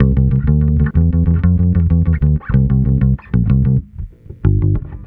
Track 13 - Bass 04.wav